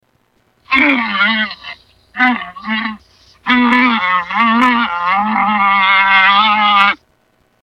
Лама тихо стонет